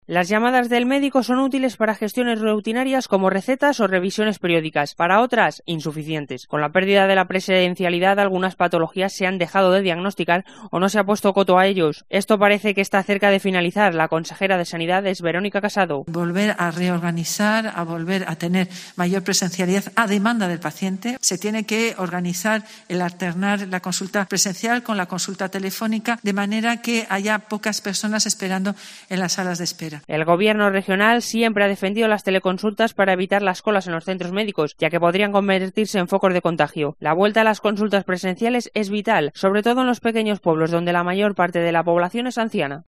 Verónica Casado, consejera de sanidad: "Volver a la demanda del paciente"